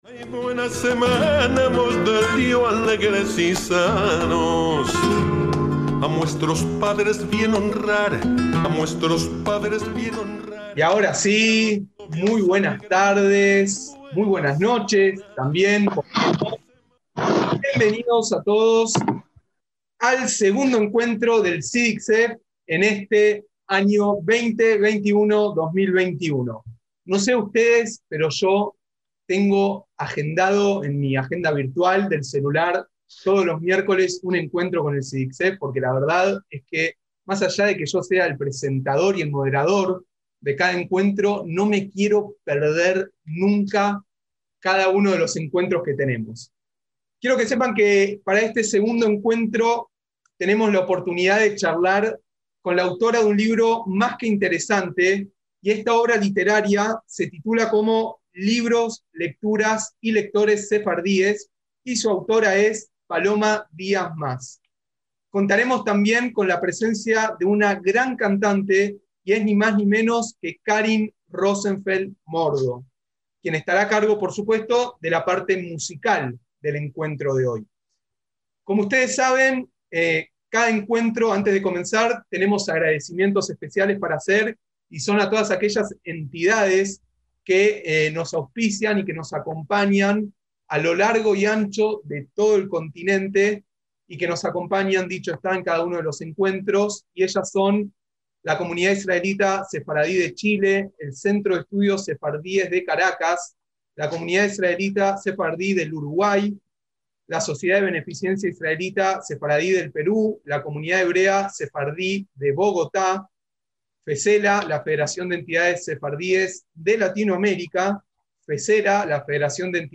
Presentación del libro "Libros